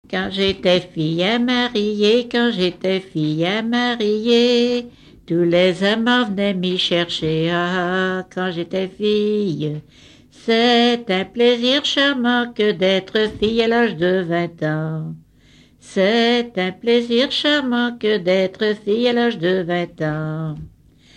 Usage d'après l'informateur circonstance : fiançaille, noce
Genre laisse
Pièce musicale inédite